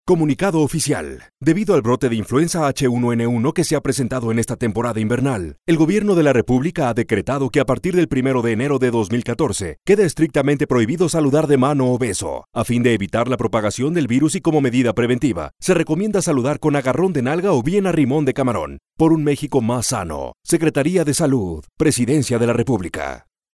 Annonces
Âge vocal : 25 à 65 ans
Styles : chaleureux, élégant, naturel, conversationnel, vendeur, corporatif, juridique, profond.
Équipement : Neumann TLM 103, Focusrite Scarlett, Aphex Channel, Source Connect